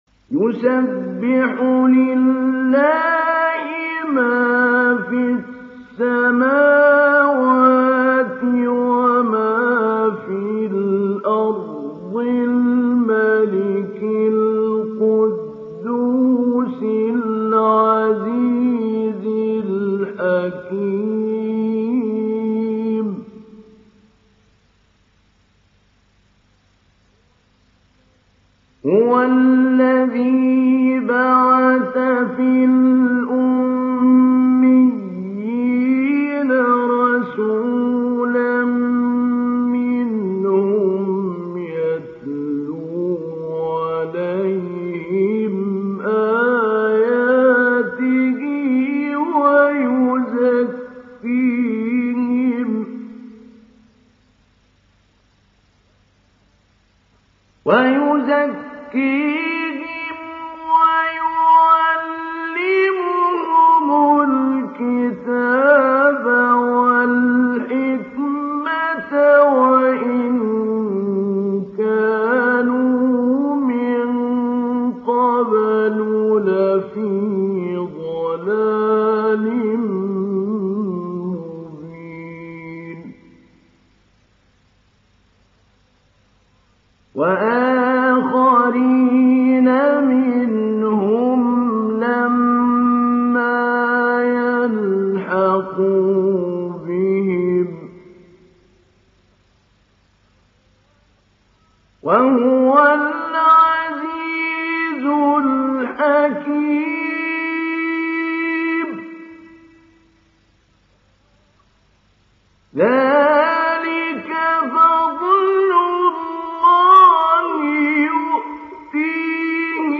Sourate Al Jumaa Télécharger mp3 Mahmoud Ali Albanna Mujawwad Riwayat Hafs an Assim, Téléchargez le Coran et écoutez les liens directs complets mp3
Télécharger Sourate Al Jumaa Mahmoud Ali Albanna Mujawwad